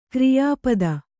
♪ kriyā pada